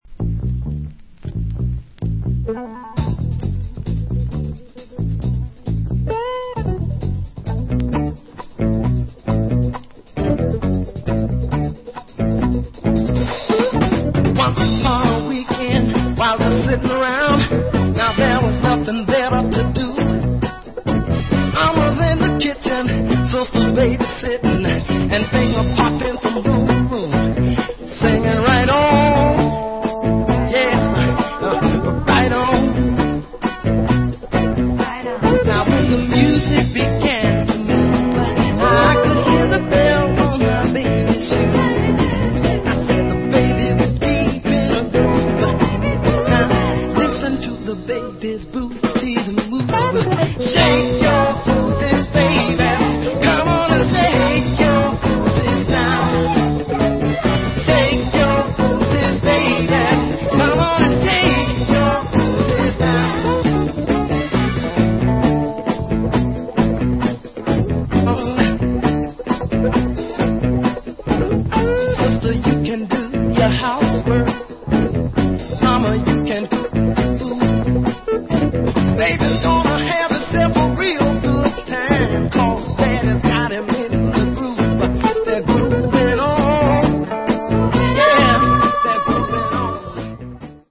.........and this is the vocal version!
70's Soul